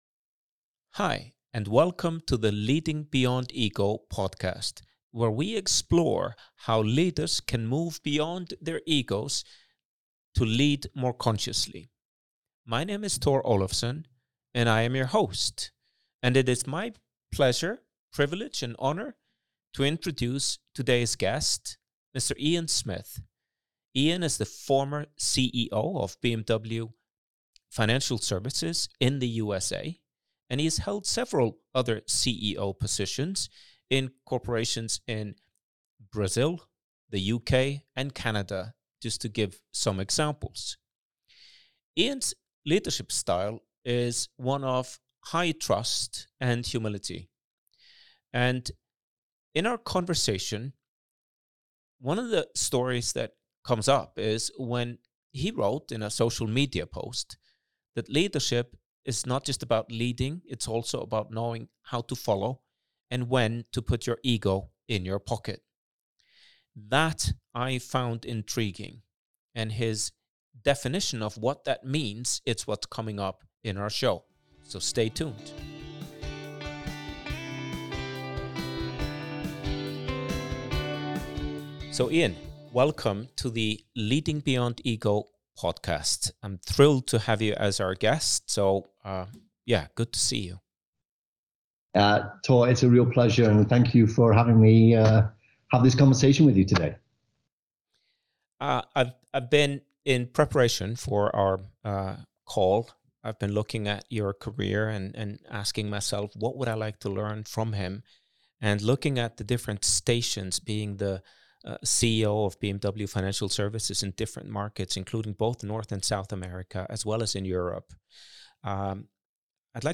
Conscious Leadership Discussion